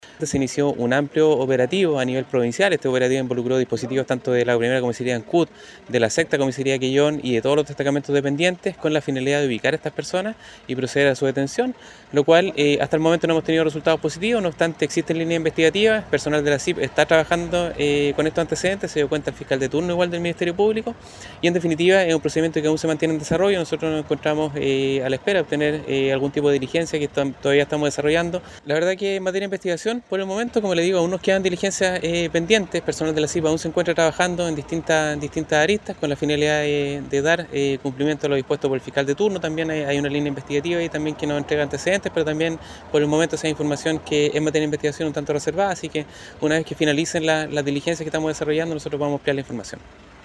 Además indicó el oficial de la policía uniformada que apenas fueron alertados de este ilícito cometido en la vía pública en Dalcahue se puso en marcha un operativo policial en toda la provincia de Chiloé, para poder dar con el paradero de los responsables de este asalto.